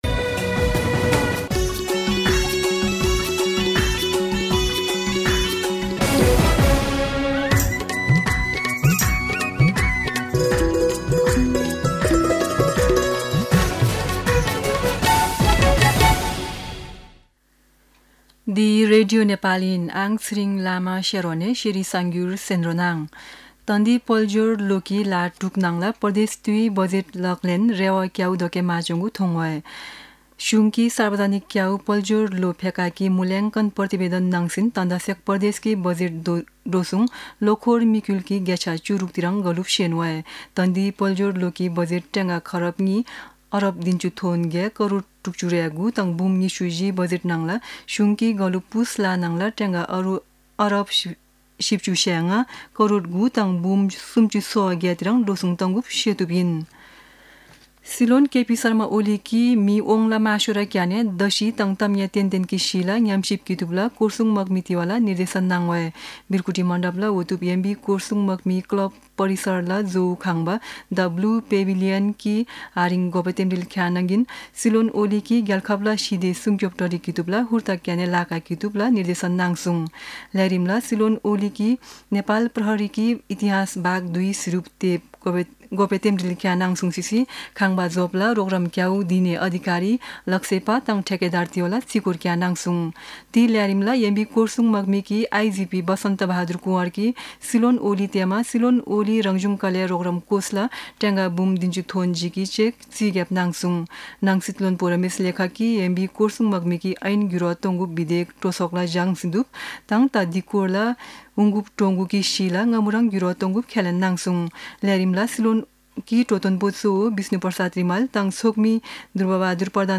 शेर्पा भाषाको समाचार : २७ माघ , २०८१